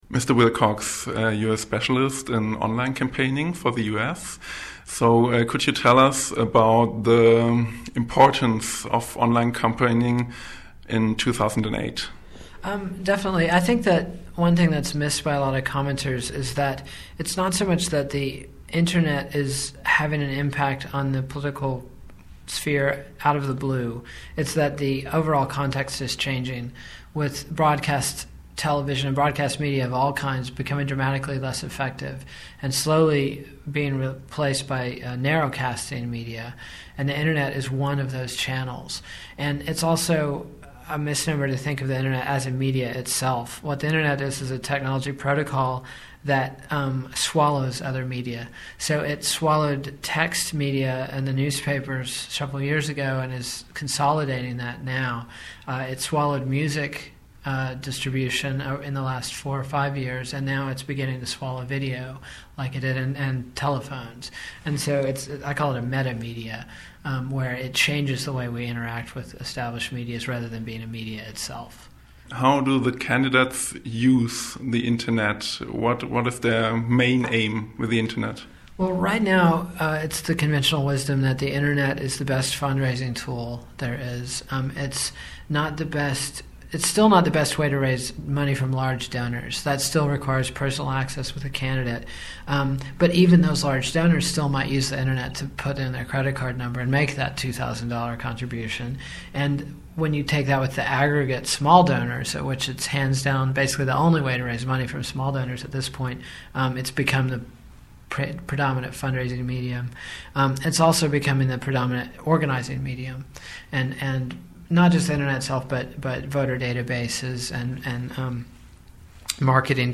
Konferenz für politische Kommunikation